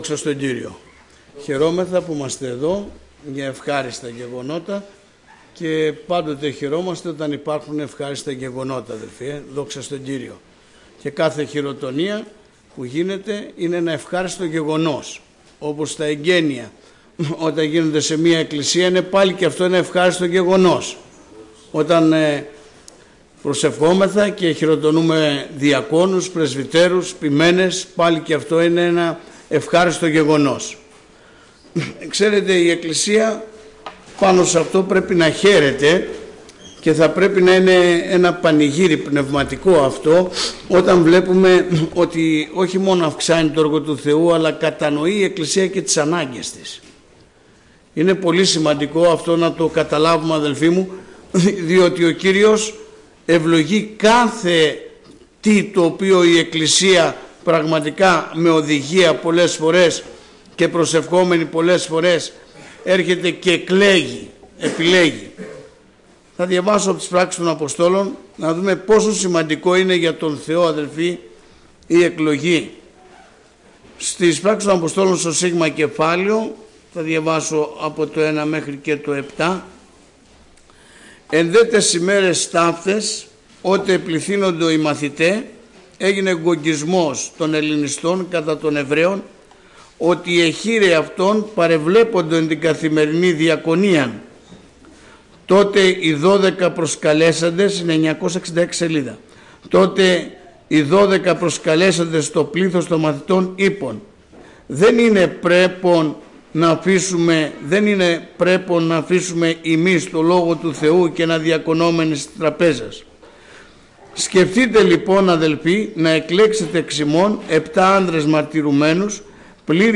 Χειροτονία διακόνων
Χειροτονία διακόνων, στις 06/03/2026, διάφοροι ομιλητές